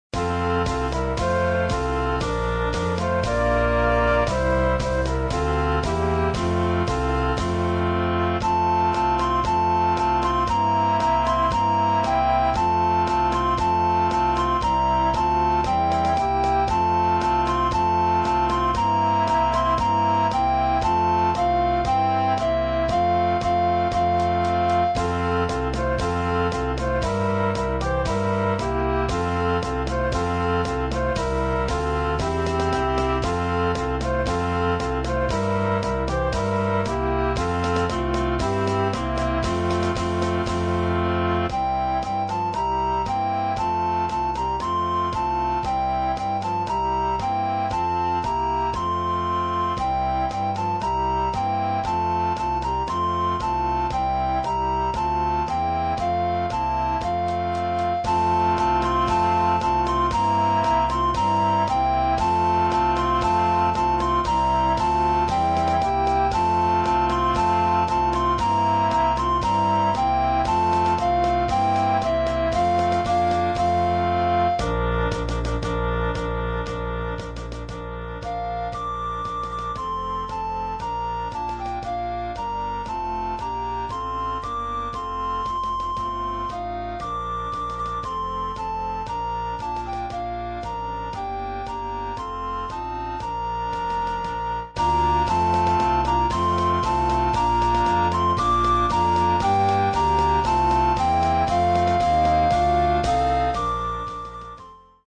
Groupe de flûtes à bec avec accompagnement orchestral
Partitions pour ensemble flexible, 4-voix + percussion.